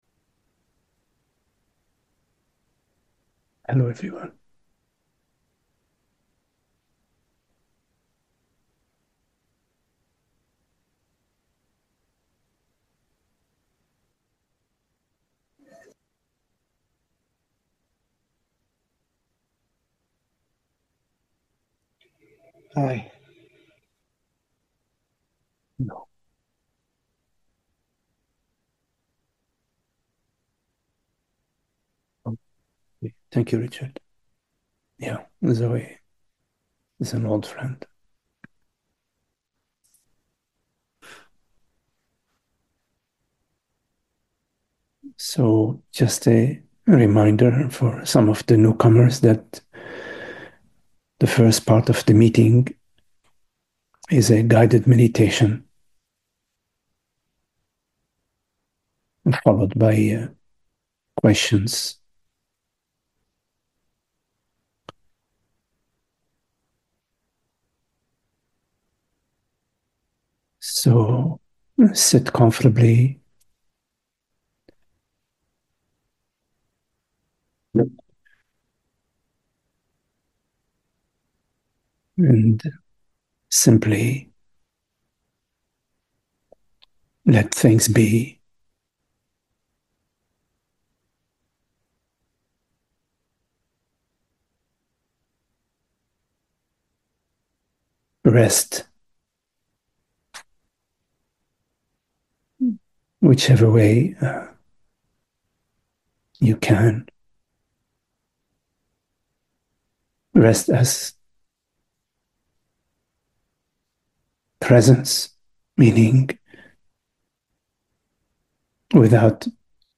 So just a reminder for some of the newcomers that the first part of the meeting is a guided meditation, followed by questions.